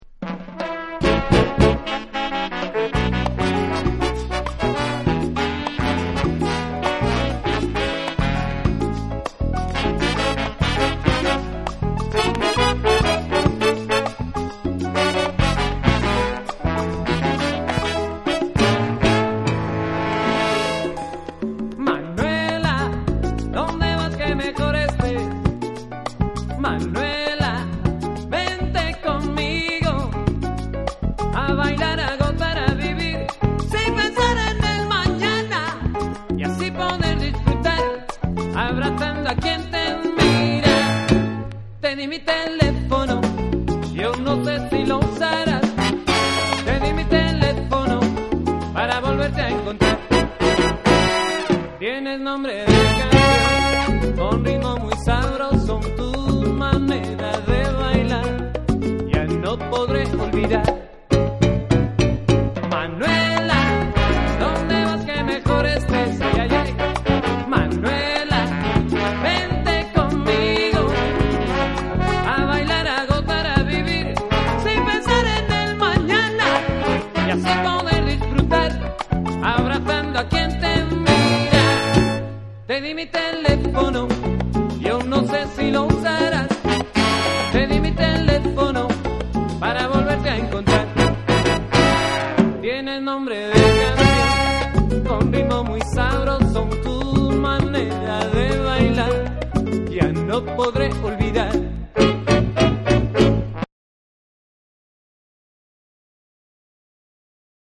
多幸なホーン・セクションに鍵盤が絡み展開したフリーソウルとしても楽しめる美メロサルサ
DJフレンドリーなビートの構成も素晴らしい！